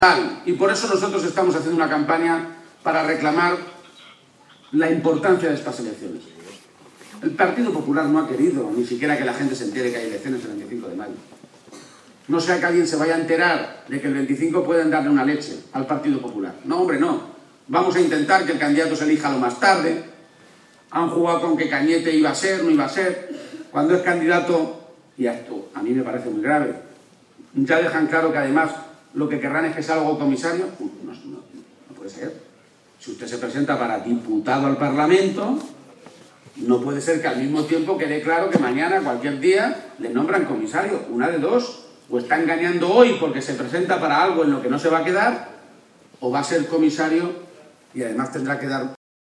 “No hay que caer en la trampa, hay que parar la coartada del Partido Popular”, insistió, en transcurso del mitin de cierre de campaña que esta noche se celebró en Albacete.